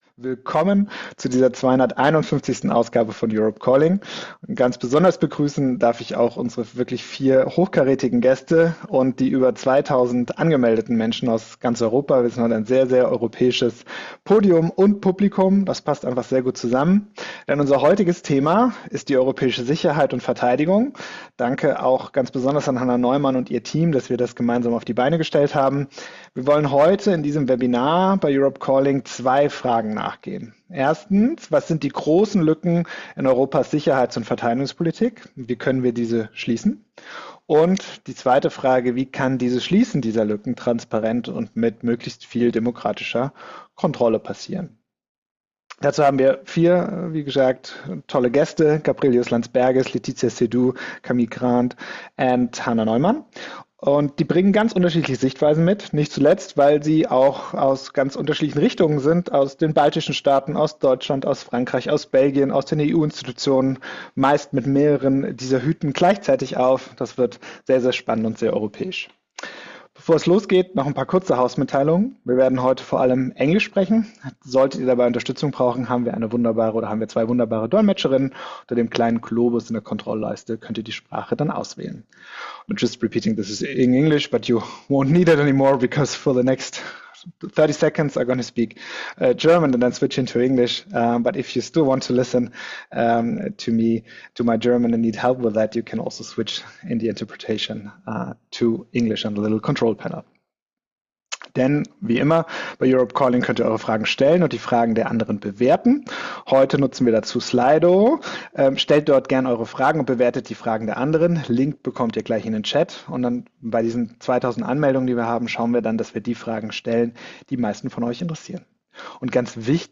- The large citizen webinar on European defence policy" (ORIGINAL) ~ Europe Calling Podcast